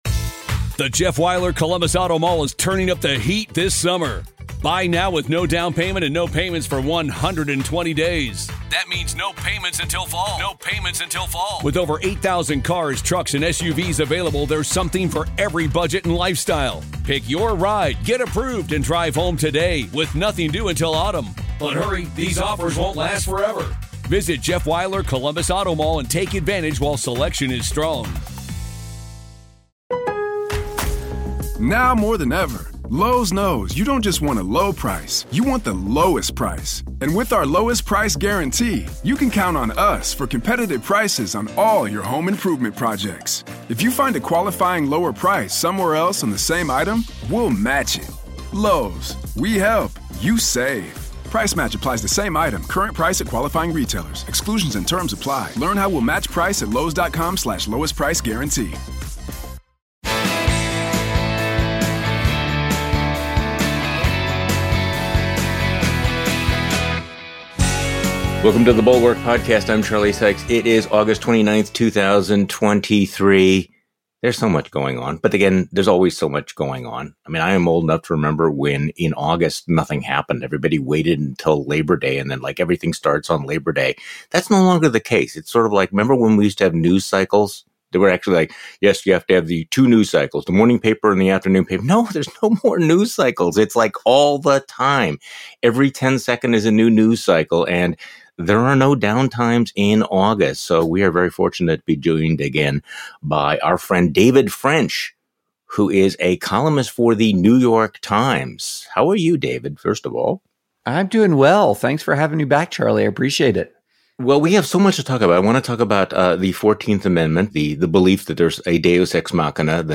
David French joins Charlie Sykes.